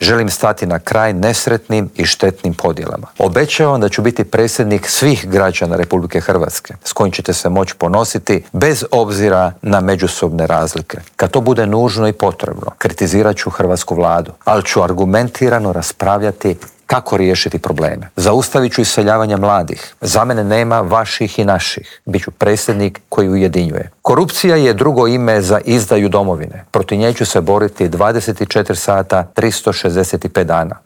U studiju Media servisa istaknuo je da živimo u vremenima globalne nesigurnosti, ratova, klimatskih promjena i demografskog slabljenja.